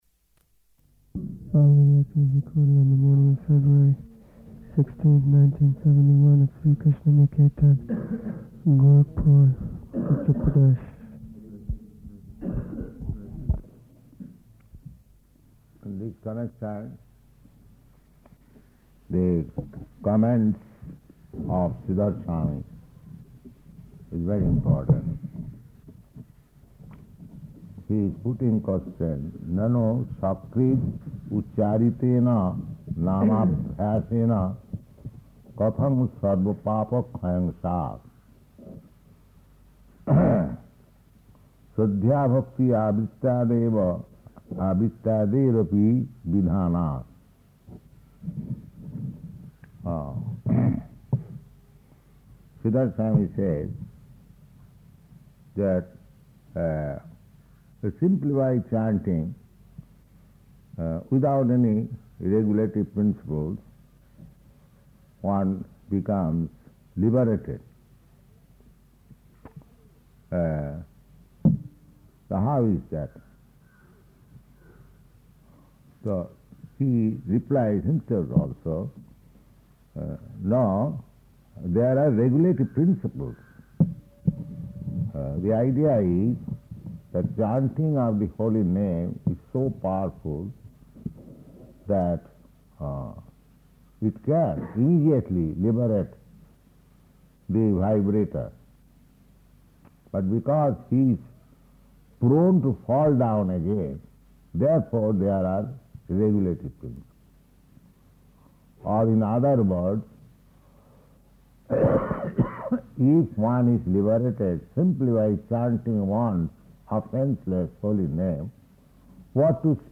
Type: Lectures and Addresses
Location: Gorakphur